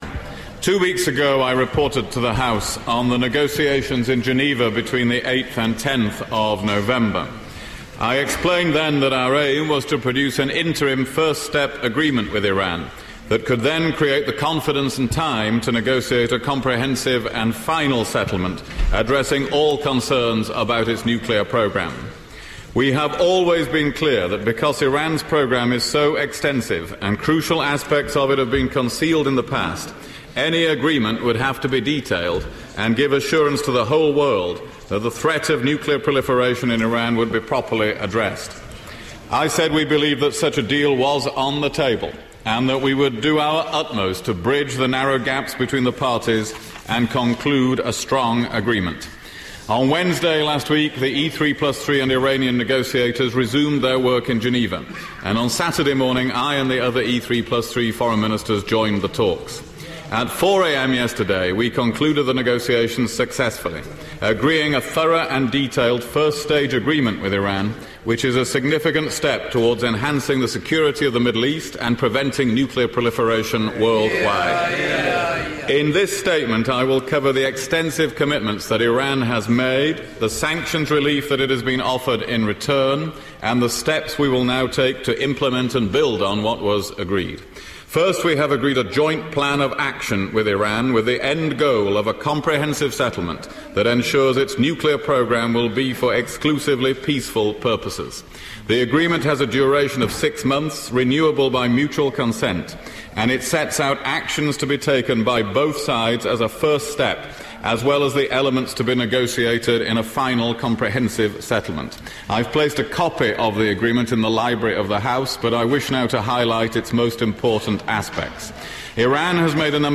William Hague's statement on the Iran nuclear deal: 'This is an important, necessary and completely justified step'
House of Commons, 25 November 2013